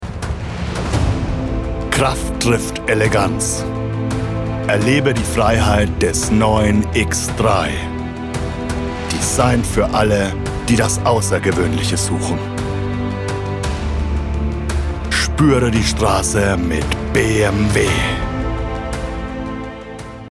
Werbung: „Dein Auto“